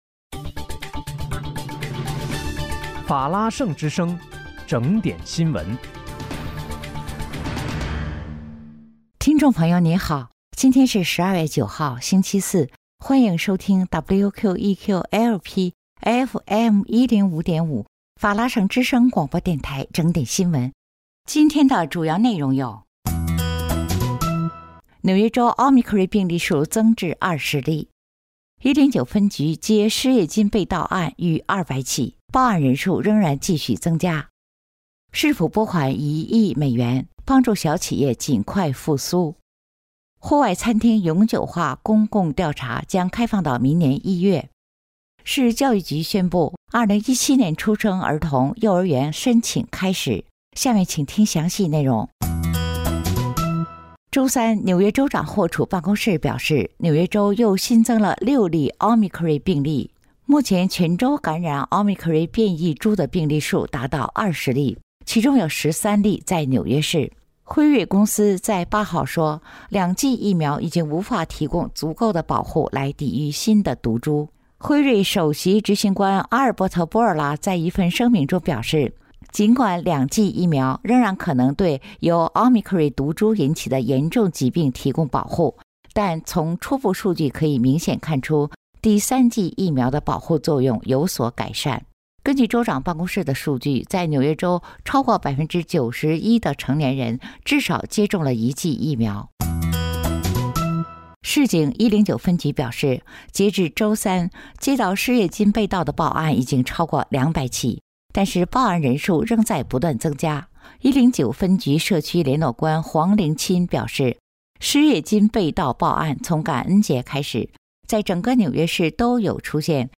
12月9日（星期四）纽约整点新闻